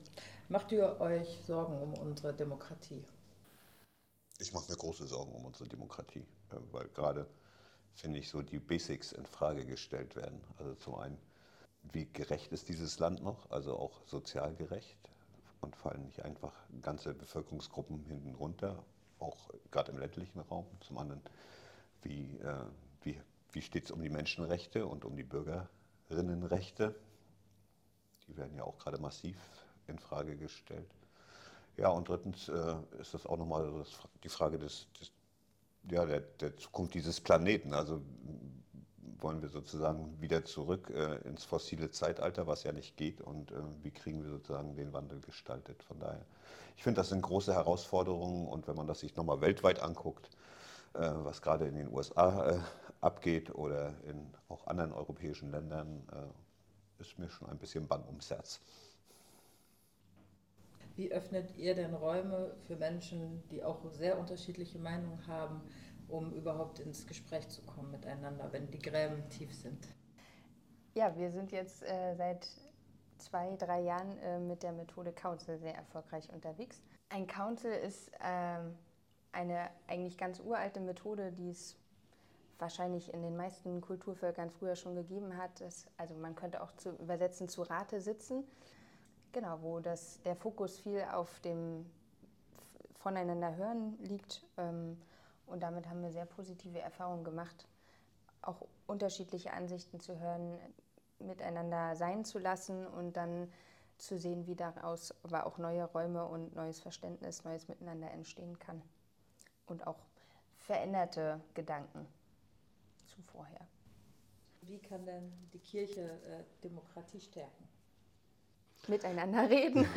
Drei Fragen an: